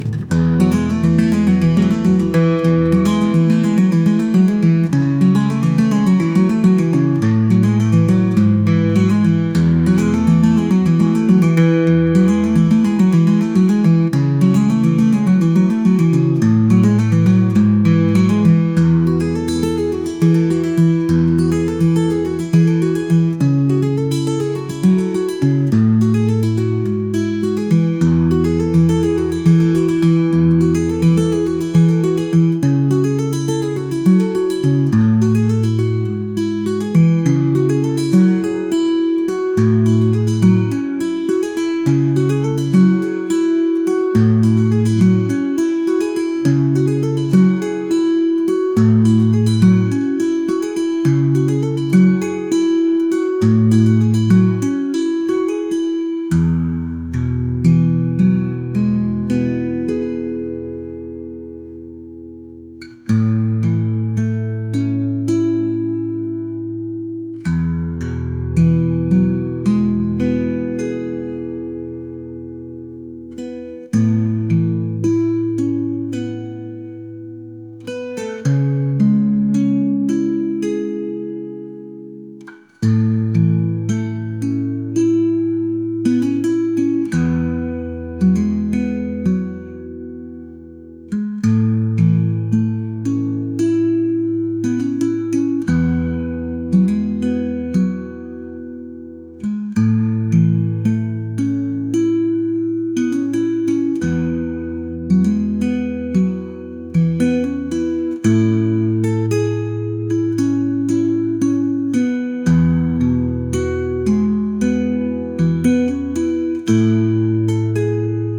folk | acoustic | indie